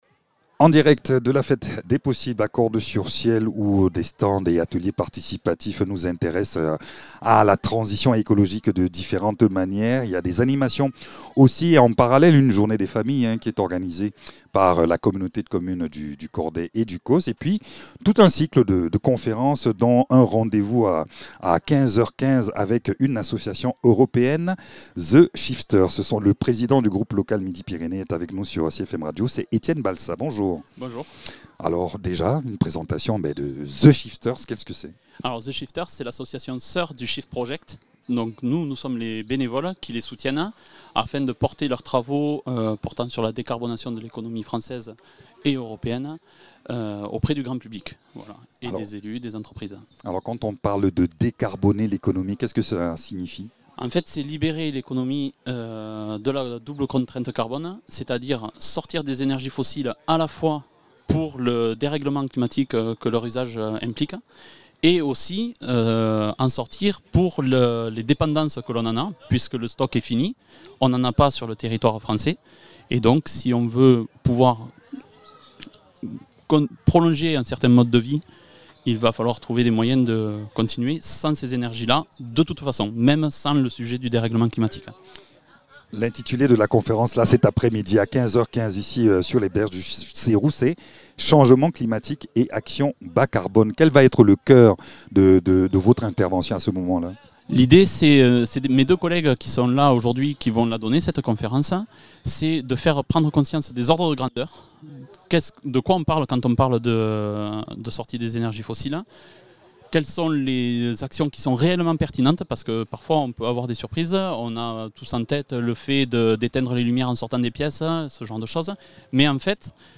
Rencontre avec cette association qui mobilise bénévoles et citoyens pour alerter, informer et agir face à l’urgence écologique. En lien avec le think?tank The Shift Project, l’association propose conférences, ateliers et actions locales pour favoriser la transition bas?carbone et permettre à chacun de contribuer concrètement à un avenir durable.